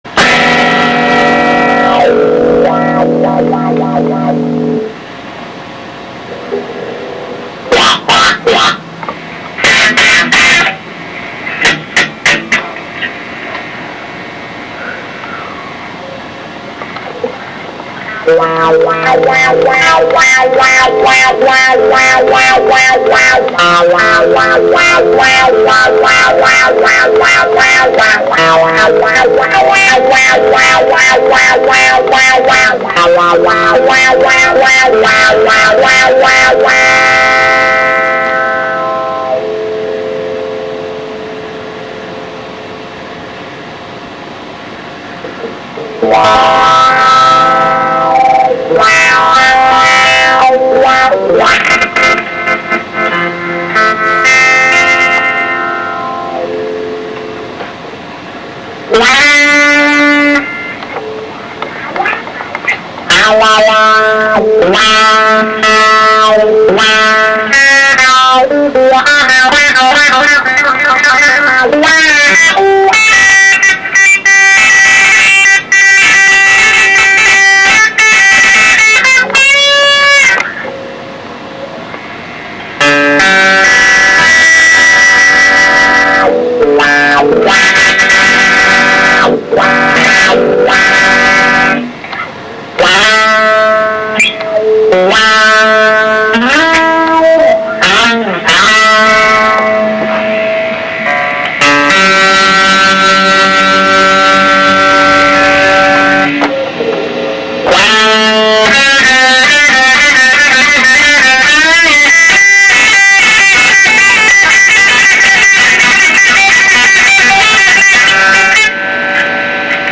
The Quantum Soup (a 40-minute improvisational exploration into the depths of sonic hedonism) - This song began as simply an outlet for my frustration at the time. I just needed to expel some negative vibes from my system, so I cranked the distortion and activated my wah pedal.
So I disconnected the wah, and then lost complete control of any sort of conventional order or structure and just played purely for the sake of the noise. Lots of feedback, lots of crunchy riffs, and there's even some ambient portions.